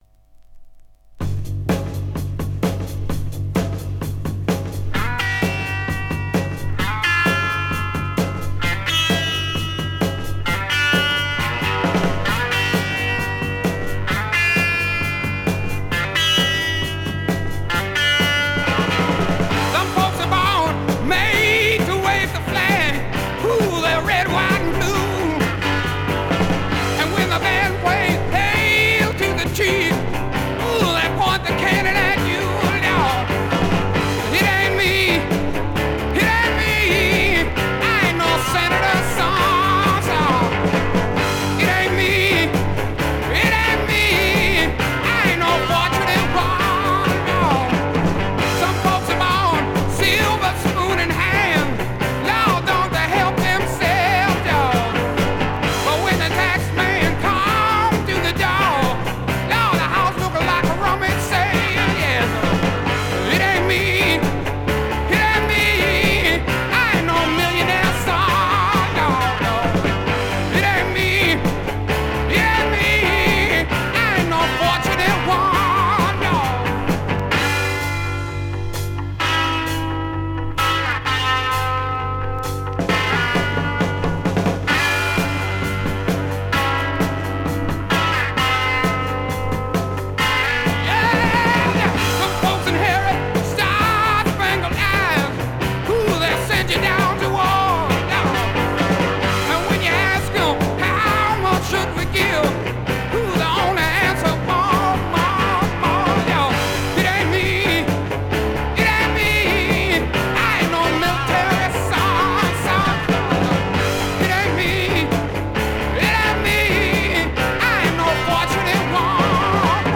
Жанр: Rock
Стиль: Classic Rock, Country Rock